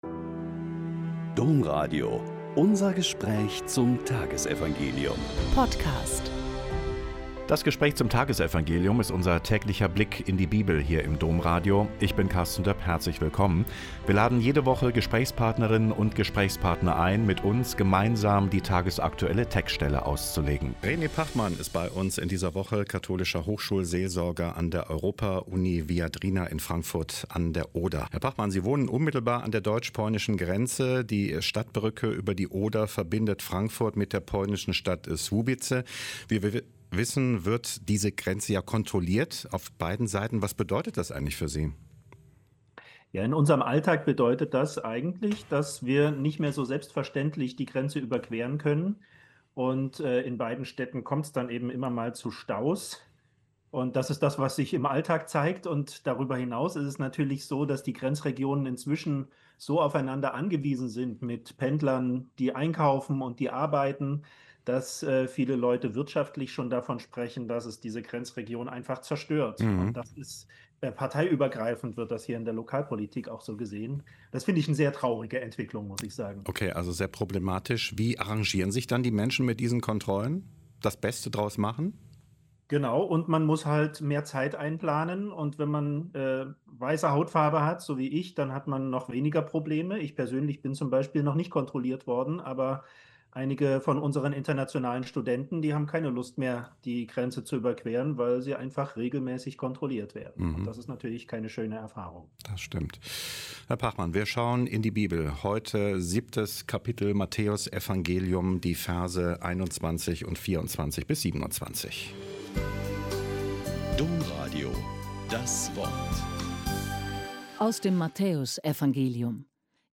Mt 7,21.24-27 - Gespräch